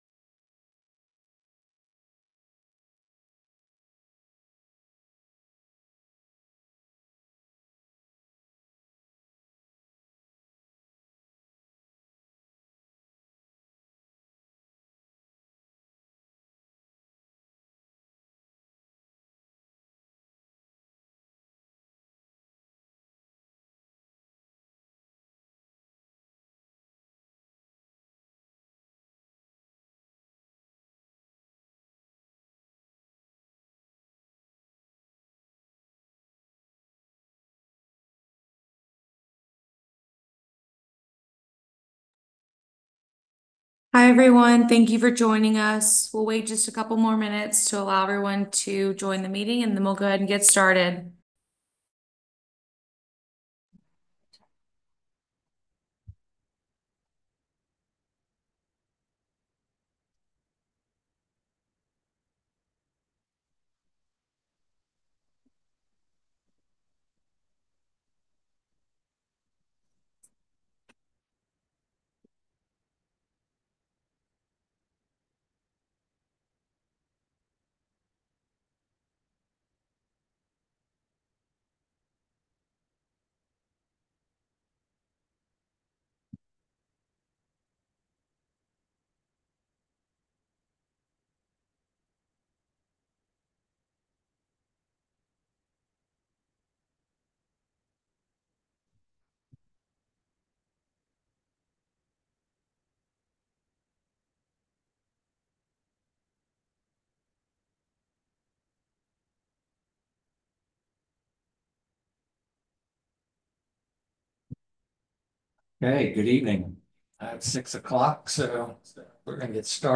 Hurricane Francine Action Plan Public Meeting, March 24
Recording-of-Hurricane-Francine-Action-Plan-Public-Meeting-March-24.m4a